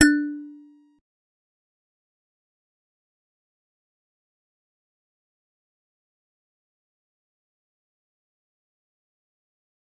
G_Musicbox-D4-pp.wav